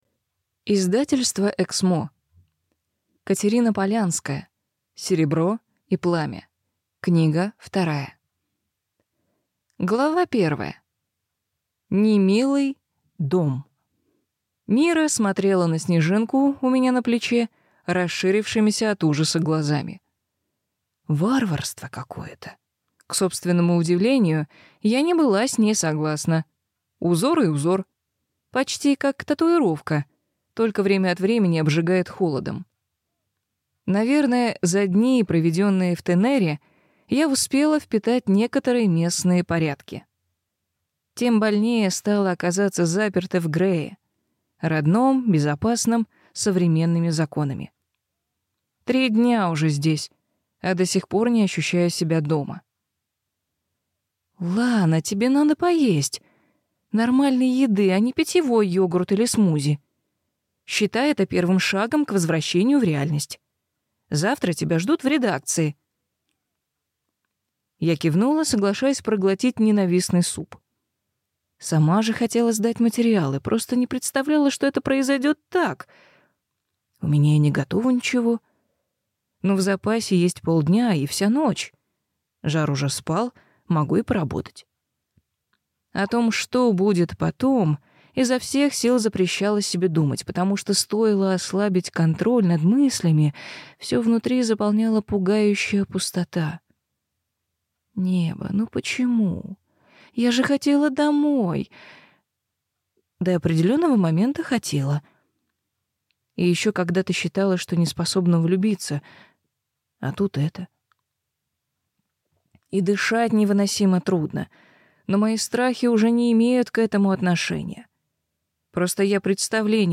Аудиокнига Серебро и пламя. Книга 2 | Библиотека аудиокниг